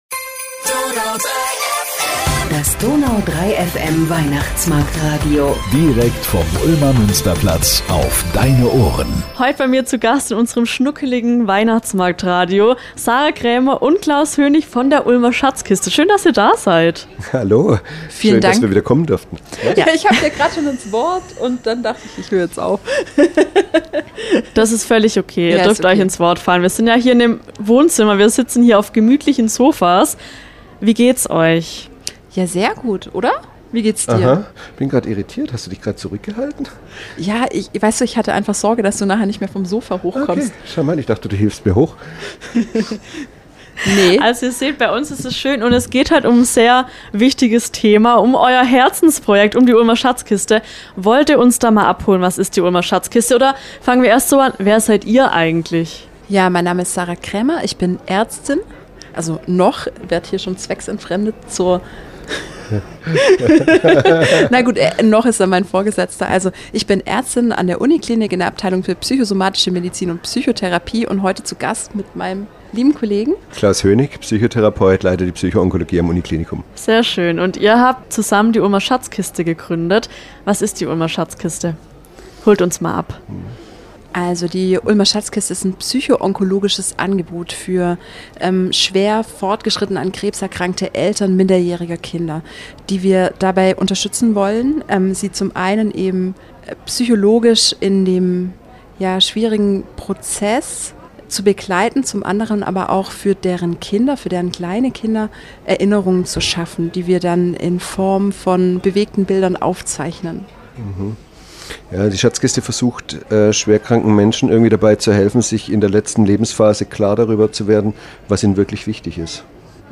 Es geht um den Prozess von der Kontaktaufnahme bis hin zum fertigen Film und wie dieses Projekt das Leben der Patientinnen und Patienten verändert. Es wird gelacht, es wird emotional, es gibt Momente, die unter die Haut gehen.